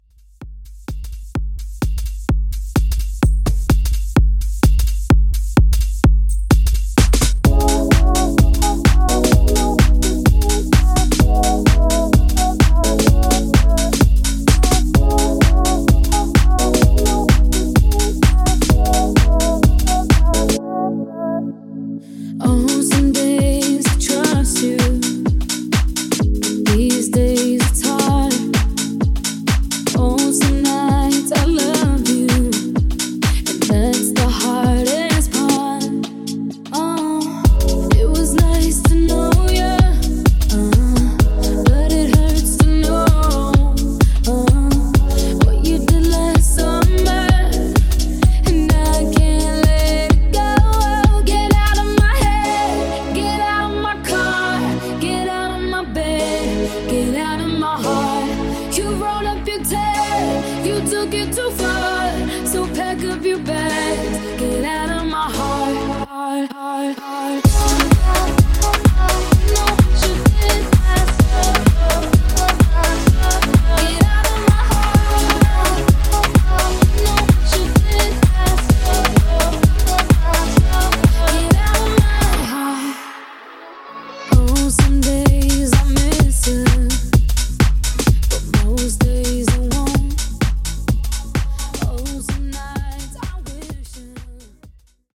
Club Redrum)Date Added